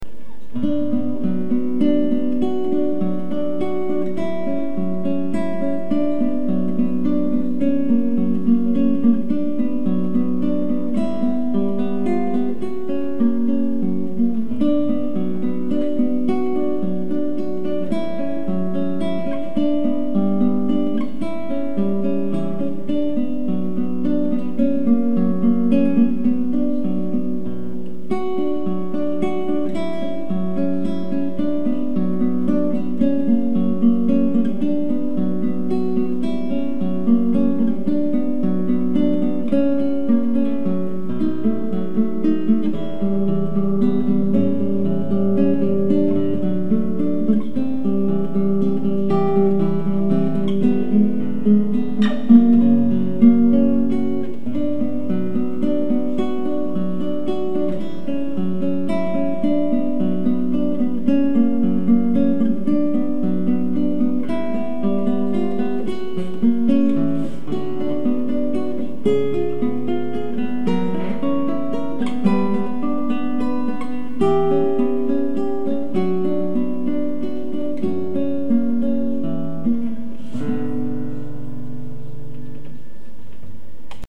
- Guitare Classique
Un poil peut être trop rapide pas assez posé à certains endroits, on ne sens pas ces courts silences qui la font rendre si belle.
etude_en_si_mineur_de_sor_168.mp3